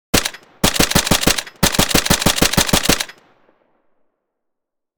Download Free Guns Sound Effects | Gfx Sounds
AR10-machine-gun-firing-bursts.mp3